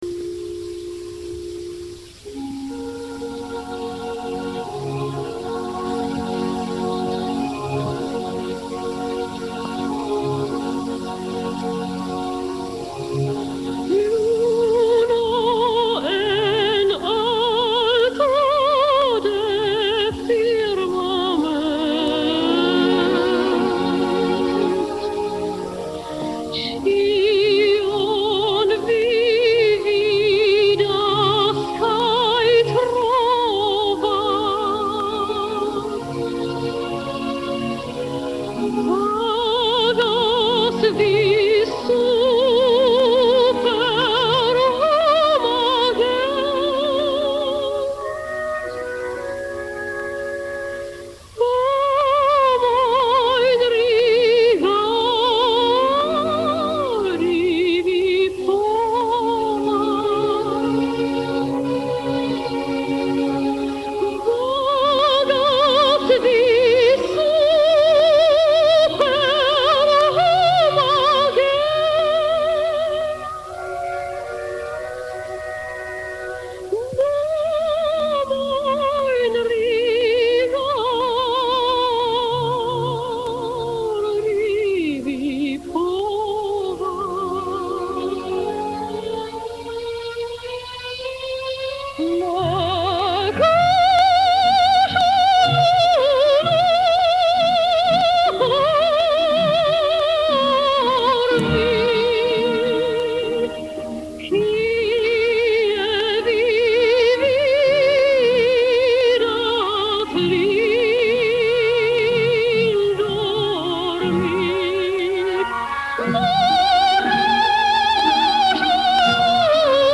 Vrcholem činnosti bylo kompletní nastudování Dvořákovy „Rusalky“ (s Karlem Högerem roli vypravěče a Marií Tauberovou a Beno Blachutem v hlavních pěveckých rolích). Tato zvuková nahrávka se jako jediná zachovala a byla následně vydána na MC i CD Českým rozhlasem bv r. 1996.
Arie_Rusalky_v_esperantu.mp3